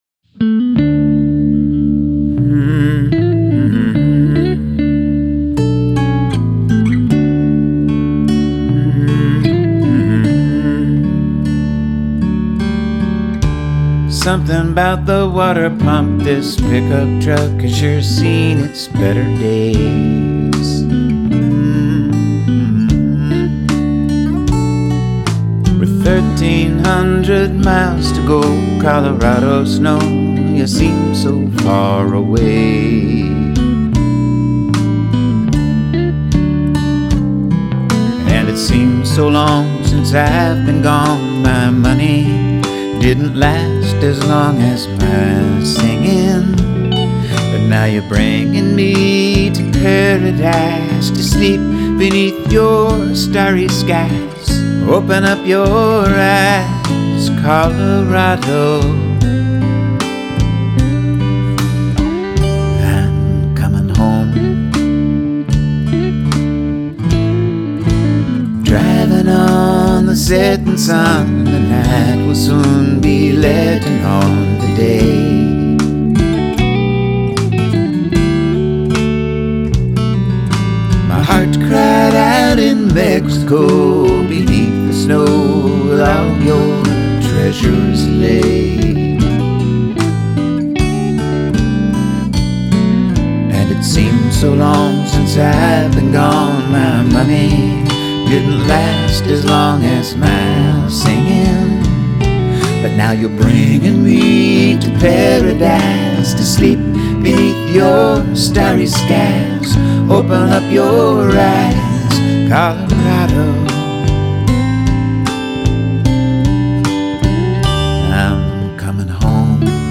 a bass part, and a super simple drum track